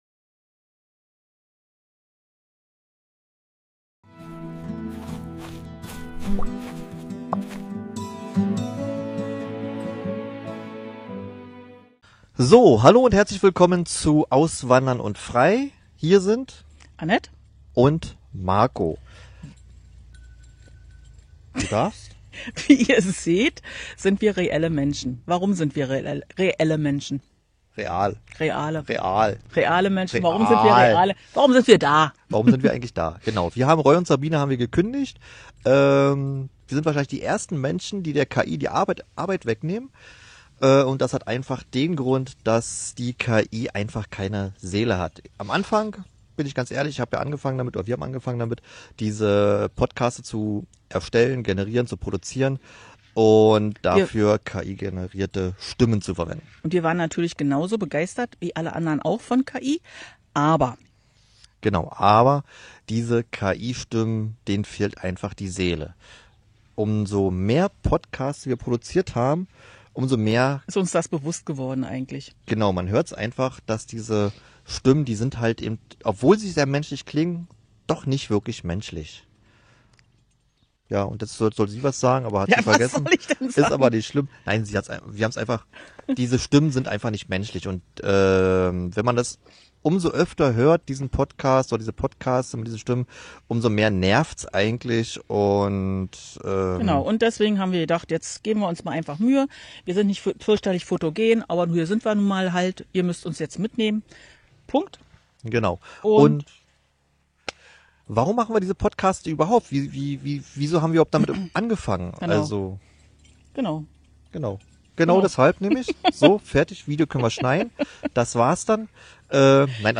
Echte Menschen, echte Infos zu Lebenshaltungskosten, Visa, Sicherheit & deinem Traum vom Leben im Ausland.